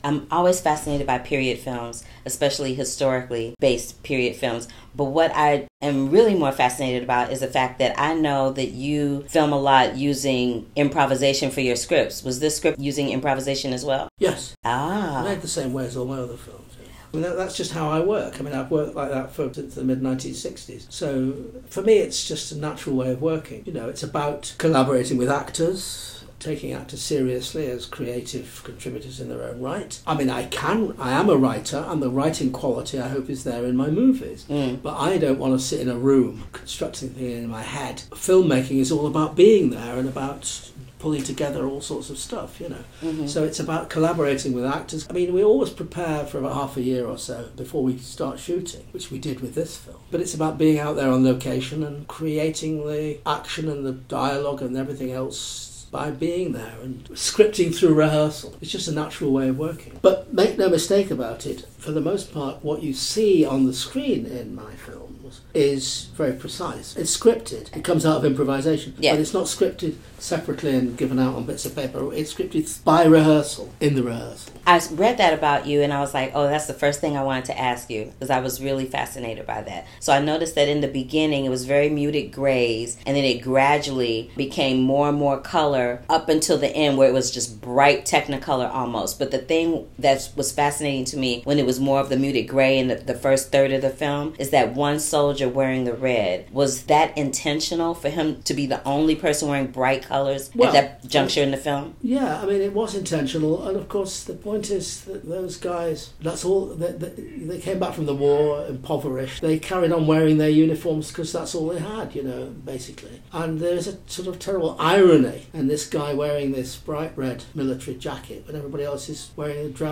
Director Mike Leigh was gracious enough to sit down and chat with me. Here are some excerpts from that conversation.
INTERVIEW WITH PETERLOO DIRECTOR MIKE LEIGH